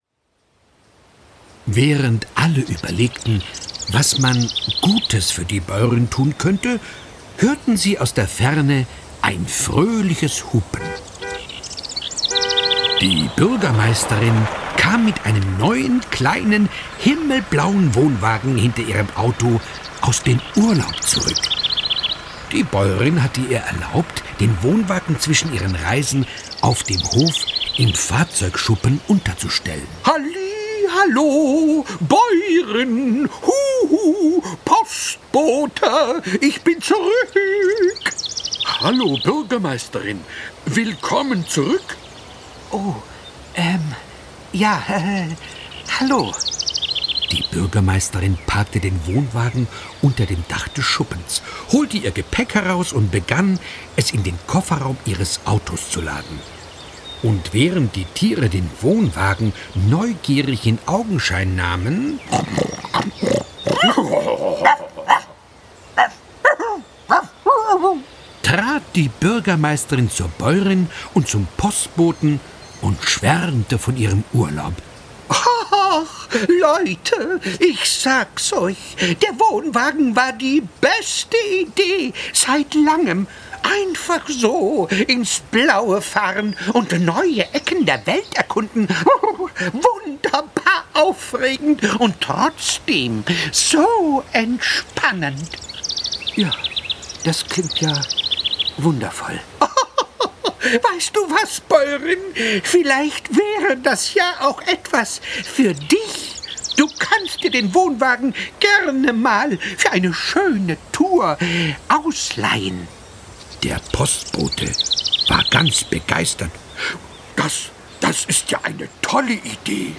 Hörspaß für Kinder ab 3 mit 6 Songs und vielen Geräuschen
Ein herrlicher Urlaubsreise-Spaß von Alexander Steffensmeier mit 6 Liedern von den Bananafishbones und vielen lustigen Geräuschen und Tierstimmen.
Gekürzt Autorisierte, d.h. von Autor:innen und / oder Verlagen freigegebene, bearbeitete Fassung.